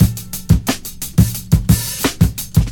• 117 Bpm Breakbeat Sample A# Key.wav
Free breakbeat - kick tuned to the A# note. Loudest frequency: 1926Hz
117-bpm-breakbeat-sample-a-sharp-key-2ln.wav